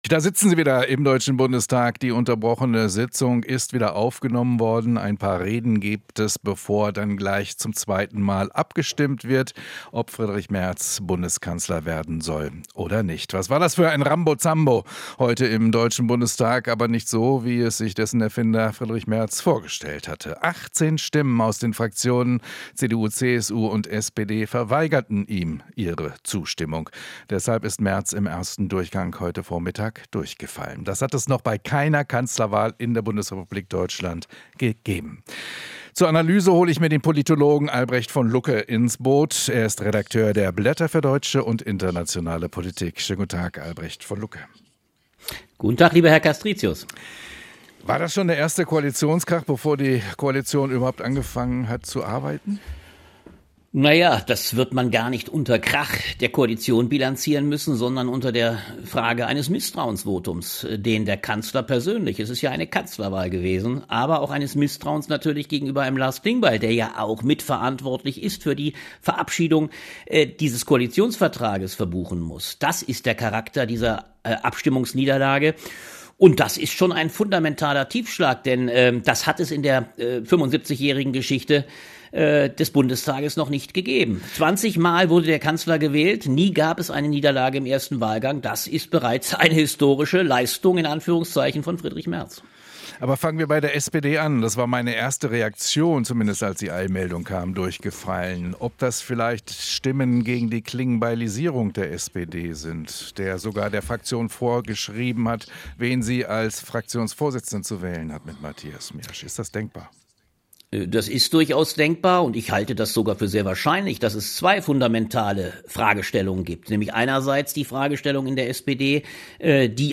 Interview - Politologe über "fundamentalen Tiefschlag" gegen Merz